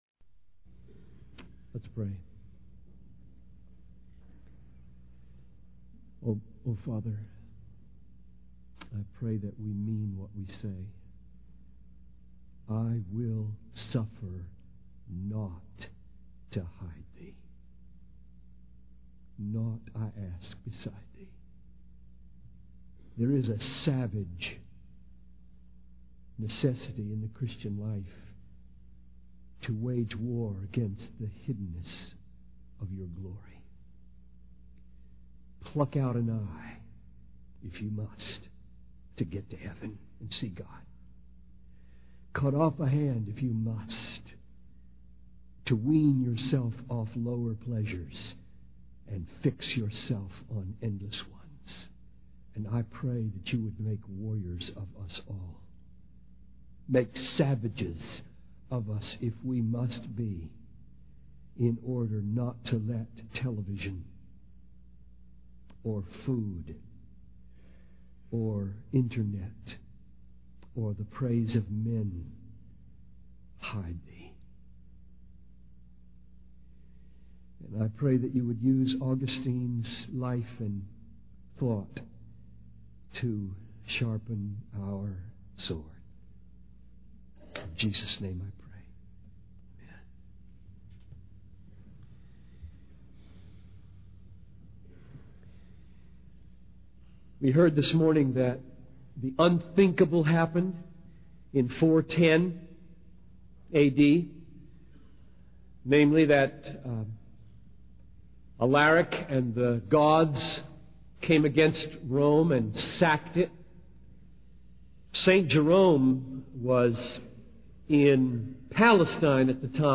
(Men of Whom the World Was Not Worthy) Augustine the Swan Is Not Silent by John Piper | SermonIndex